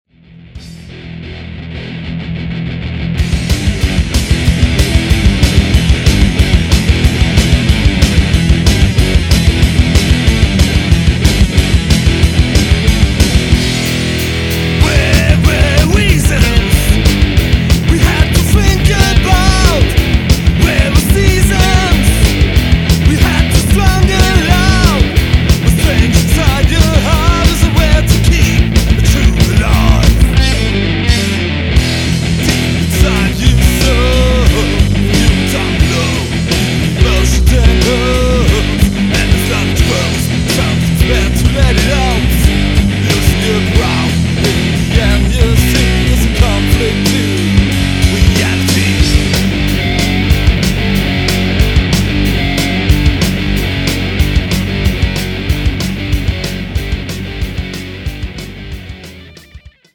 Heavy-Melodic-Metal
Keyboards
Rhythm Guitar
Lead Guitar
Bass Guitar